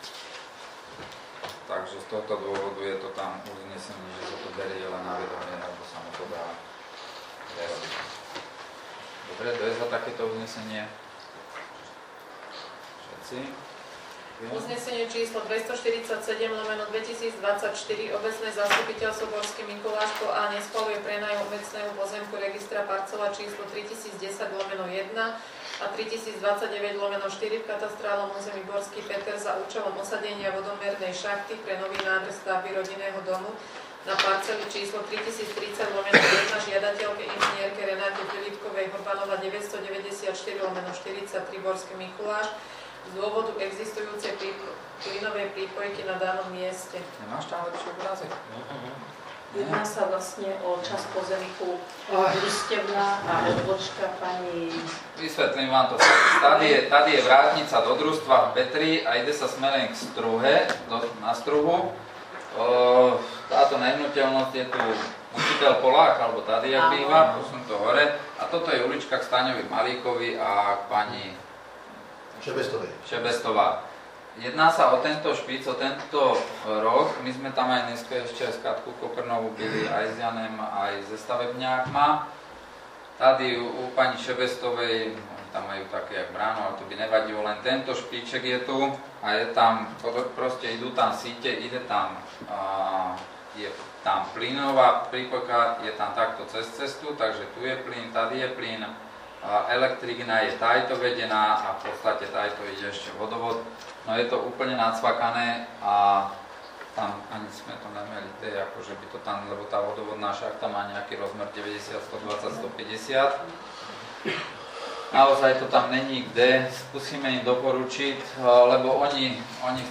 Borský Mikuláš - Portál elektronických služieb | Elektronické služby | Registre | Zvukový záznam z 11. zasadnutia OZ